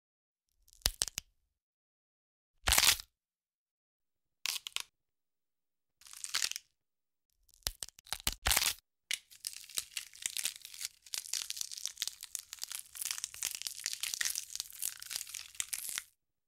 دانلود صدای شکستن استخوان یا کمر 3 از ساعد نیوز با لینک مستقیم و کیفیت بالا
جلوه های صوتی